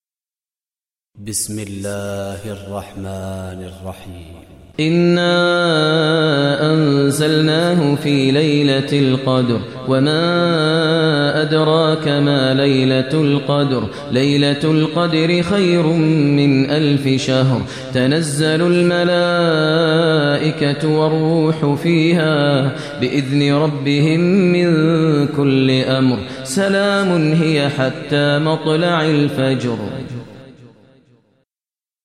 Surah Qadr Recitation by Maher al Mueaqly
Surah Qadr is 97 chapter of Holy Quran. Listen online mp3 tilawat / recitation in Arabic recited by Sheikh Maher al Mueaqly.